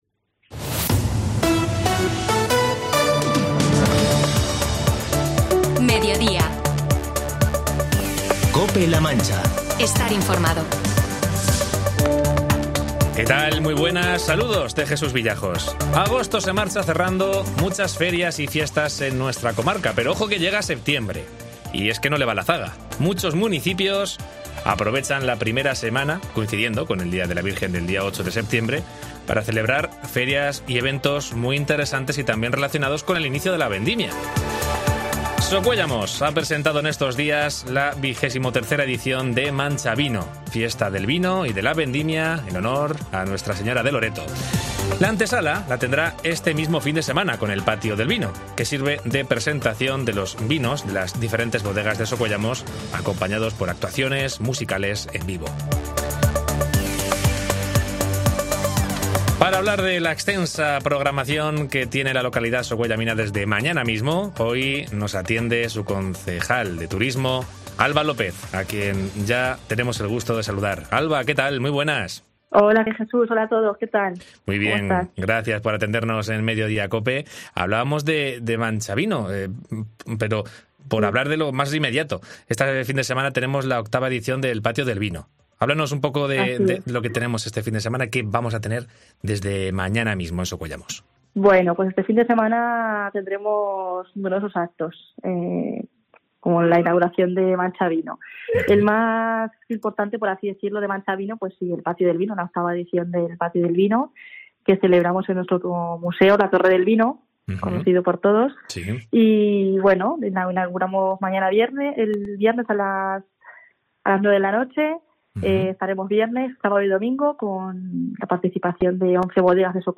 Entrevista a Alba López, concejal de turismo del Ayuntamiento de Socuéllamos